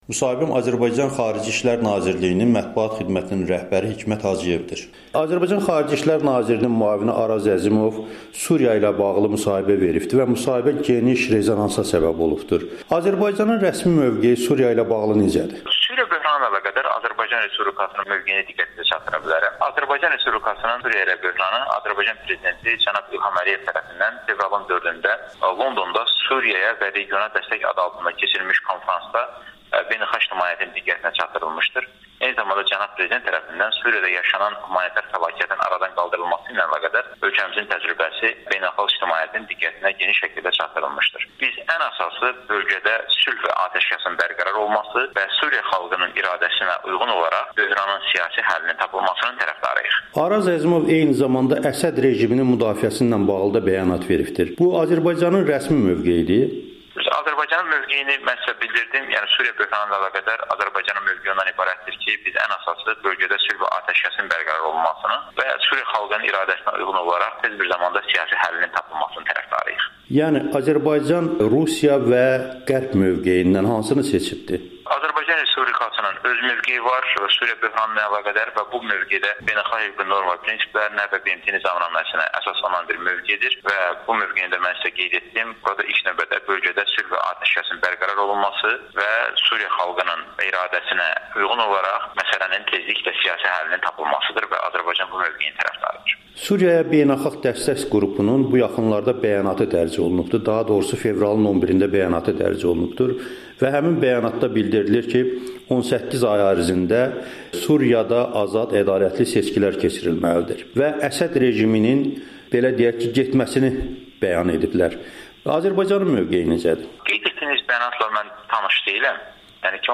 Hikmət Hacıyevin Amerikanın Səsinə müsahibəsi